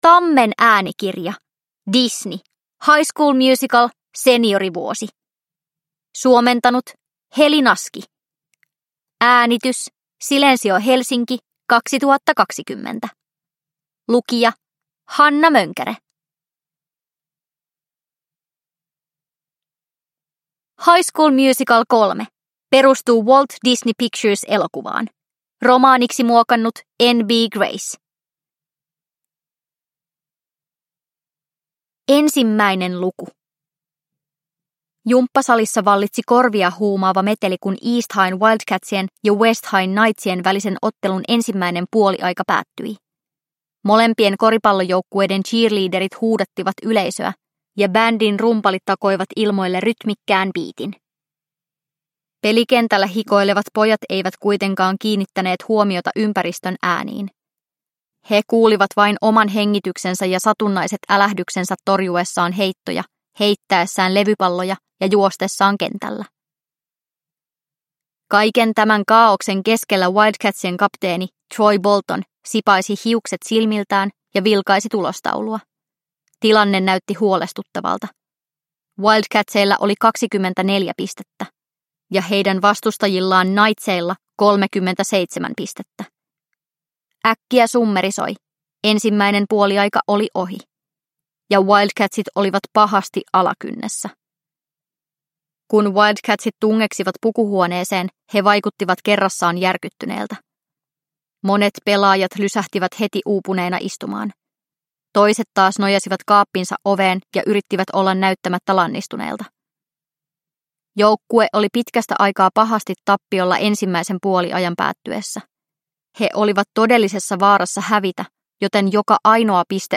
High School Musical. Seniorivuosi – Ljudbok – Laddas ner